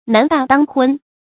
男大當婚 注音： ㄣㄢˊ ㄉㄚˋ ㄉㄤ ㄏㄨㄣ 讀音讀法： 意思解釋： 指男子成年后要娶親成家 出處典故： 明 朱鼎《玉鏡臺記 議婚》：「自古道： 男大當婚 ，女長須嫁。」